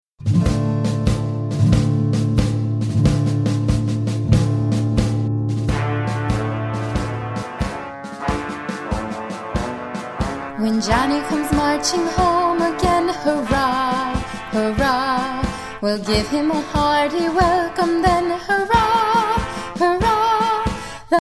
Traditional Patriotic Homecoming Song